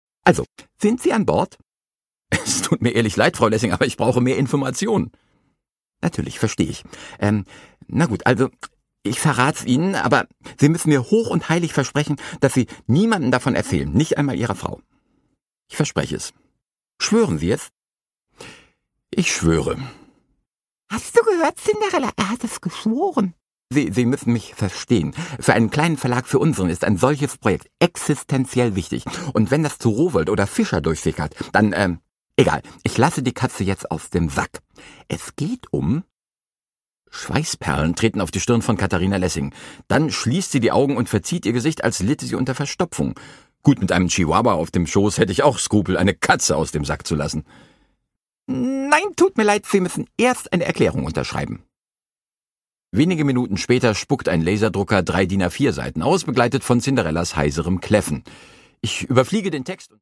Produkttyp: Hörbuch-Download
Gelesen von: Moritz Netenjakob